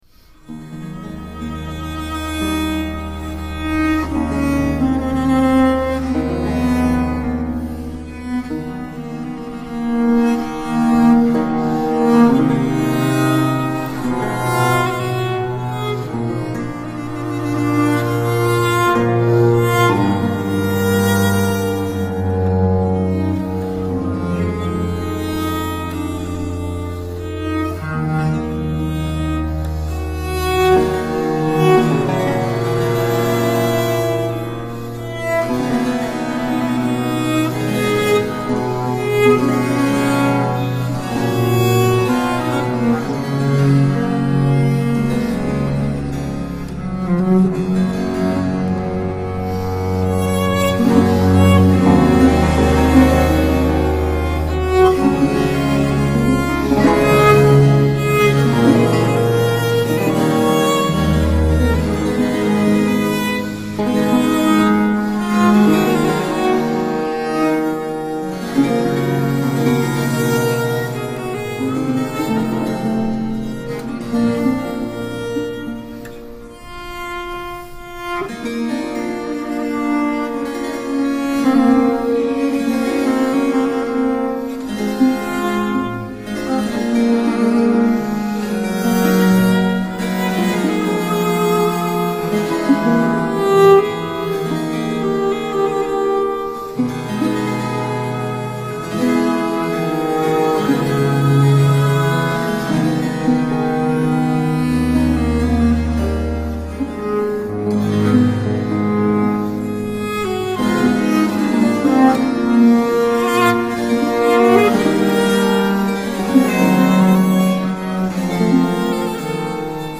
Tombeau
Group: Instrumental
Marin Marais - Tombeau pour M. de Sainte-Colombe, for viola da gamba and continuo in E minor